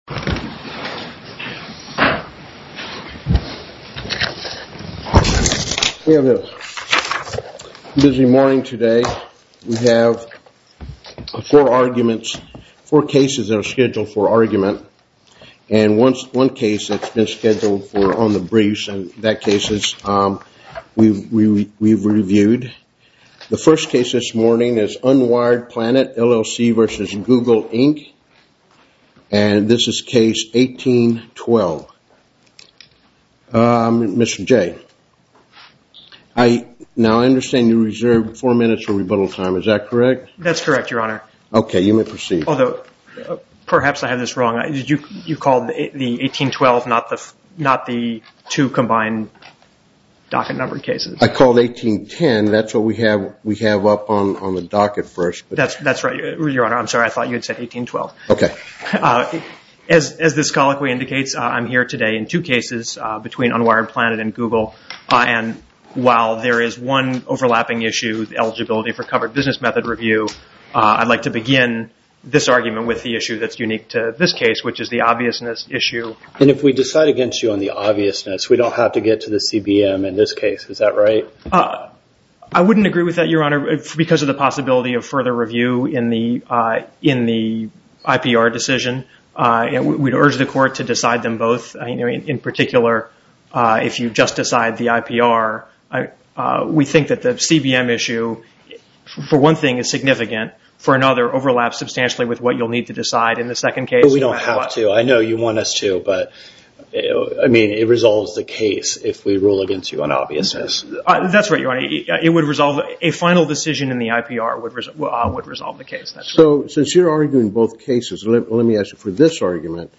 Oral arguments
In oral arguments, Unwired Planet argued that the U.S. Patent 7,024,205 is not directed to covered business method and PTAB had no business instituting the CBM. From questioning, the judges appear inclined to avoid the question of CBM review by finding invalidity by reasons of obviousness in an IPR on the same patent proceeding in parallel with the CBM review. Judges grill Google's representative on whether this patent relates to a financial service starting at 30:28.